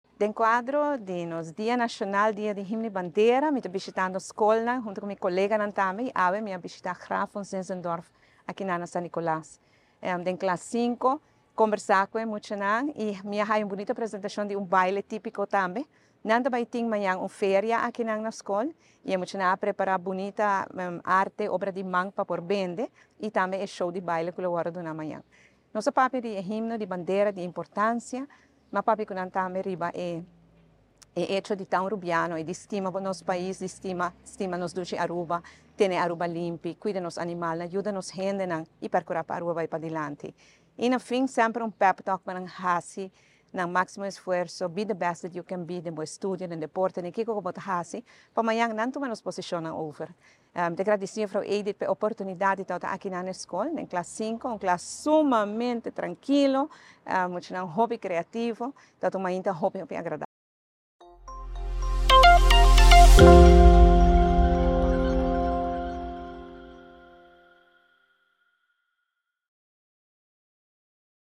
ORANJESTAD – Diahuebs mainta, Prome Minister Evelyn Wever-Croes a cuminsa su dia bishitando Graf von Zinzendorf School na San Nicolas en conexion cu dia di Himno y Bandera.